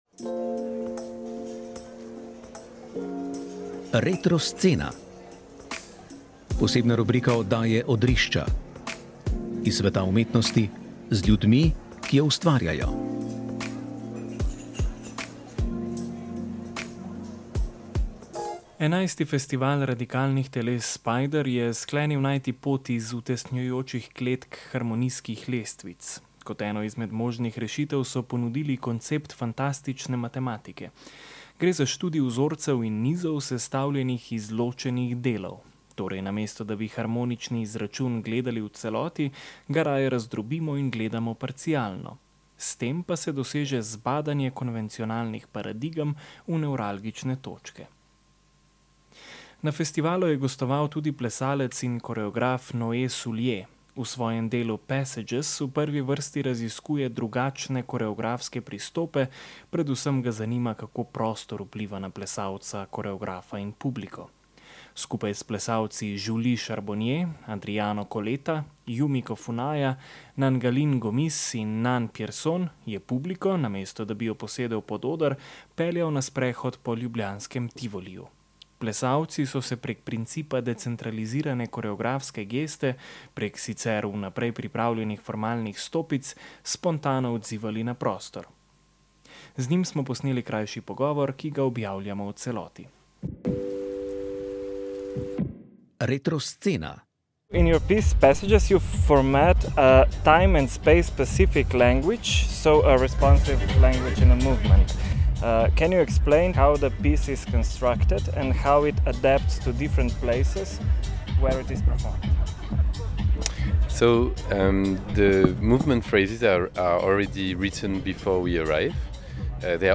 Odrišča so bila na 11. Festivalu radikalnih teles Spider.